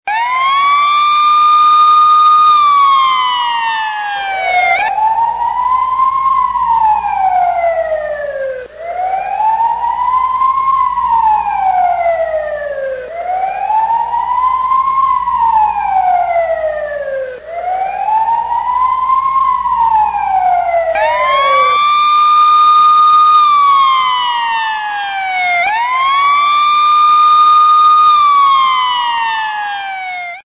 huozaijingbaosheng.mp3